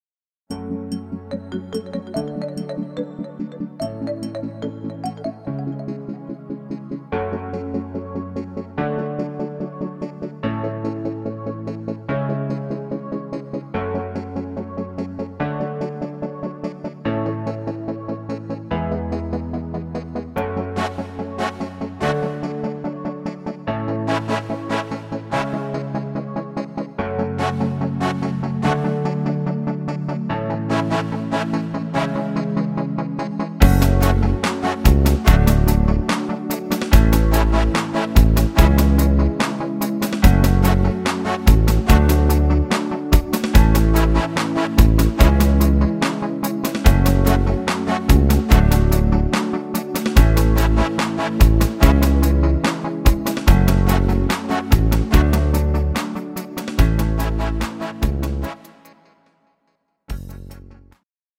Rhythmus  Pop Rap